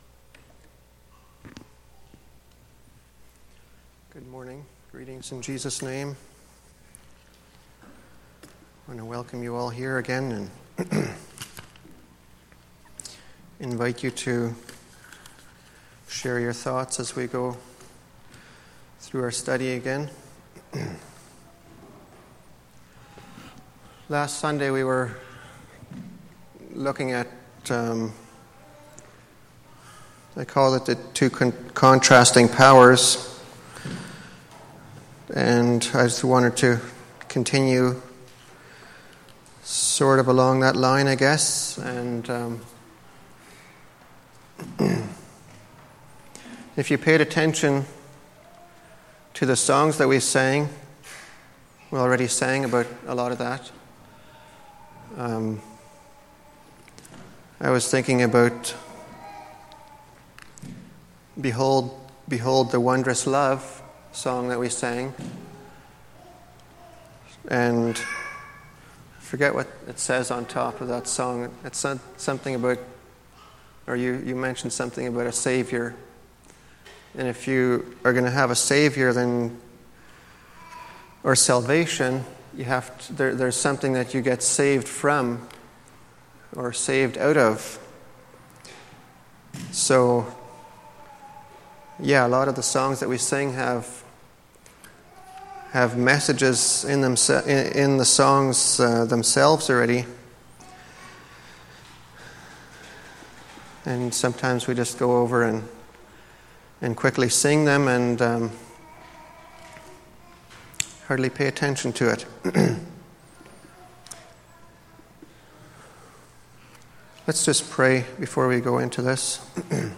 Sunday Morning Bible Study